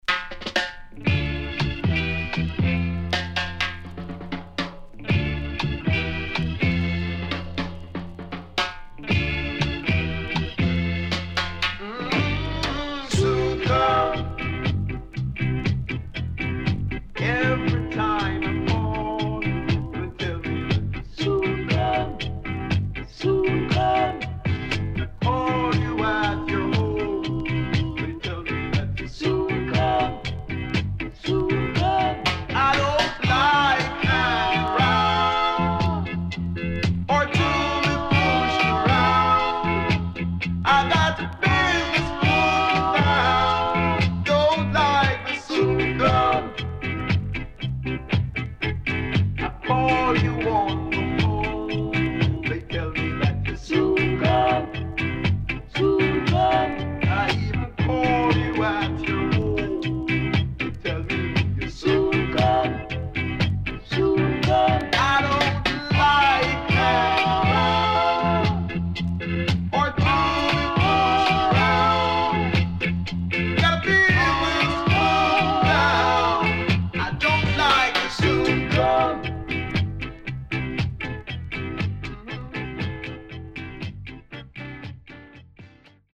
Early Reggae